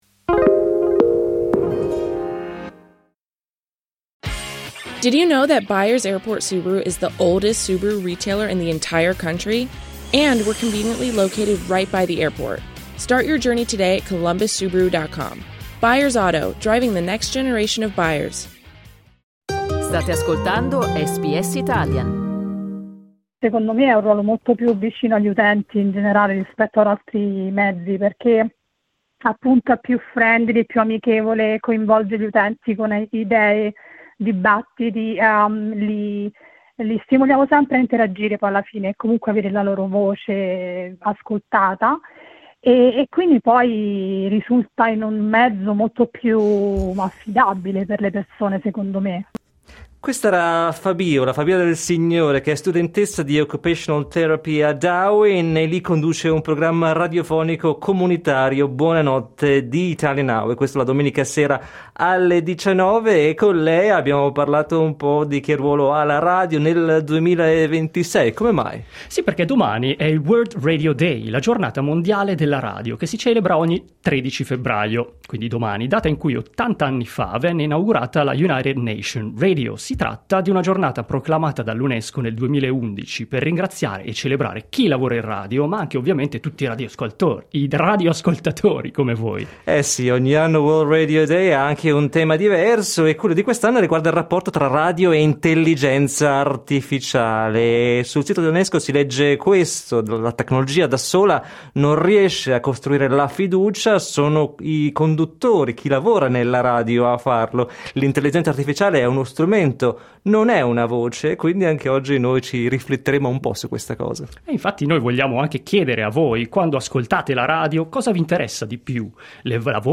Alla vigilia del World Radio Day, insieme a degli ospiti abbiamo trattato il tema di quest’anno: il rapporto tra radio e IA e le nuove regole dell’ACMA per la trasparenza.